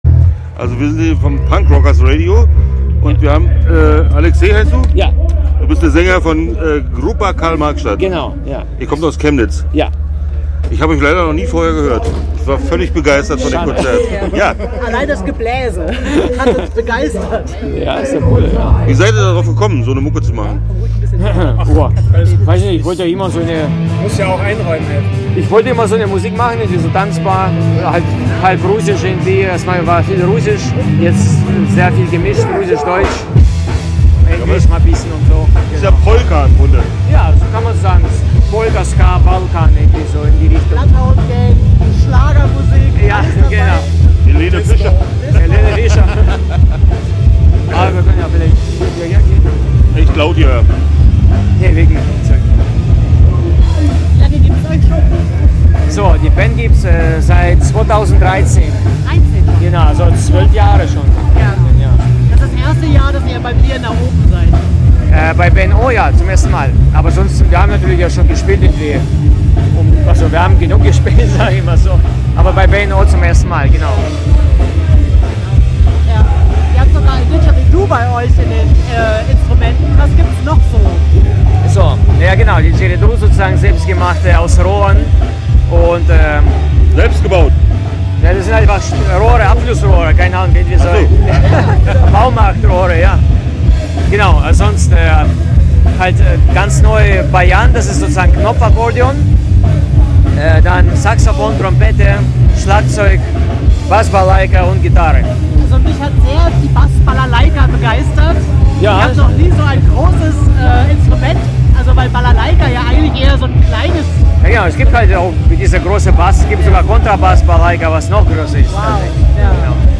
Start » Interviews » Gruppa-Karl-Marx-Stadt